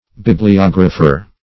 bibliographer - definition of bibliographer - synonyms, pronunciation, spelling from Free Dictionary
Bibliographer \Bib`li*og"ra*pher\, n. [Gr. ?, fr. ? book + ? to